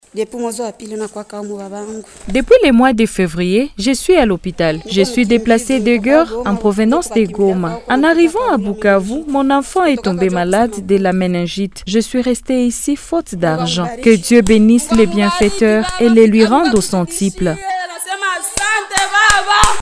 Une dame déplacée de guerre en provenance de Goma dont le mari était décédé et qui assurait la garde de son enfant souffrant de la méningite proclame sa joie après sa sortie de l’hôpital.